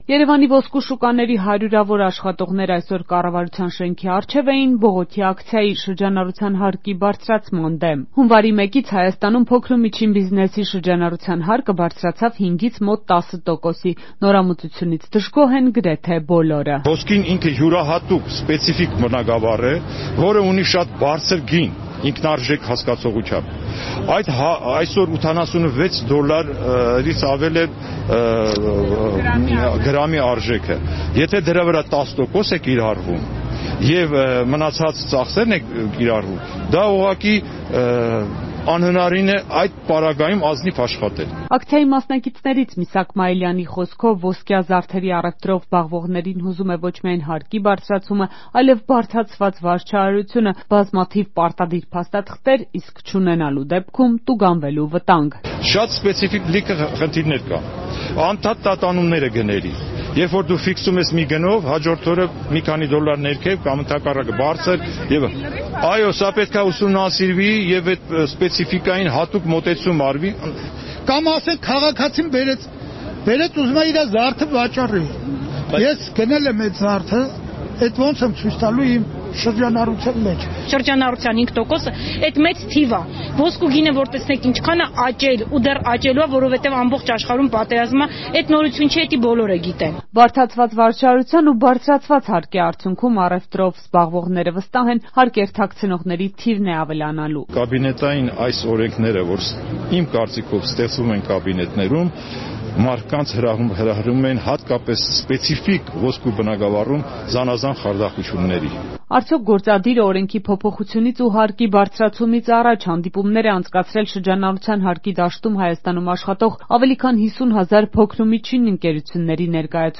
Ռեպորտաժներ
Երևանի ոսկու շուկաների աշխատողները կառավարության առջև էին՝ շրջհարկի բարձրացման դեմ ցույցի